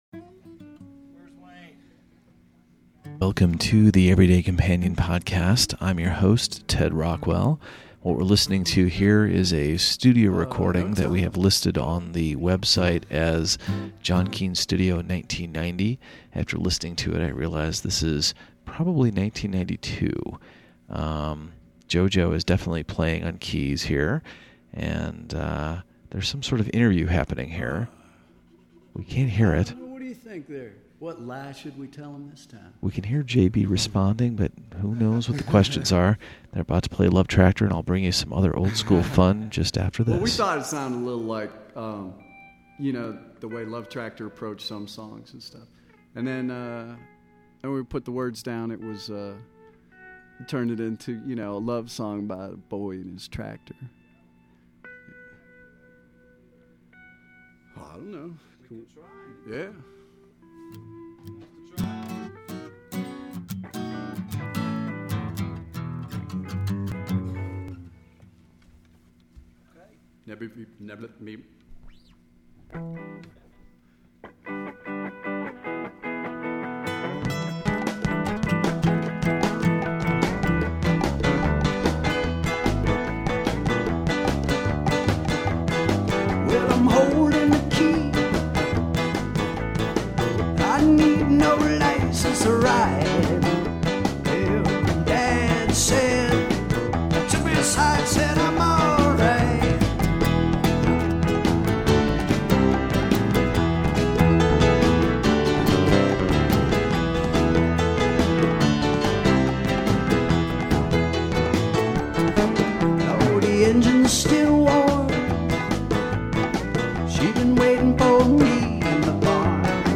Studio recording 9/92
Little John Coliseum, Clemson, SC